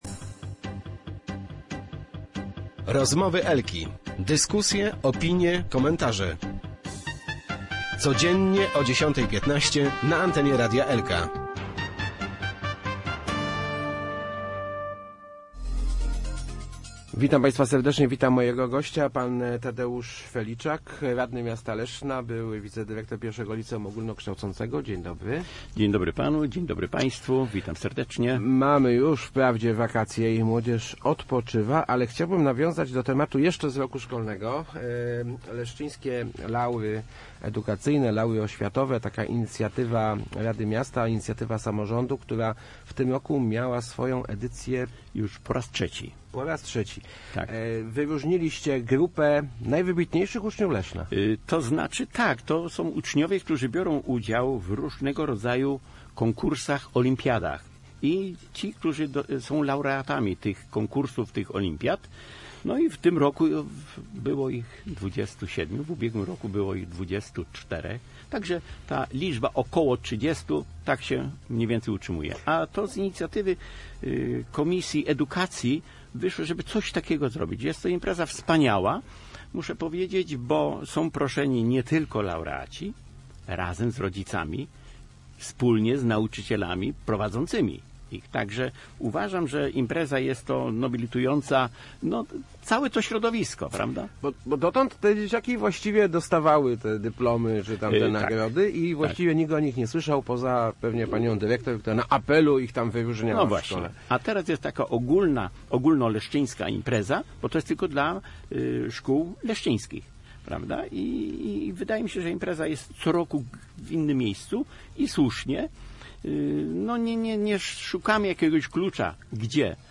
– Chcemy, by inspirowały do nauki kolejnych młodych ludzi – powiedział w Rozmowach Elki radny Tadeusz Feliczak.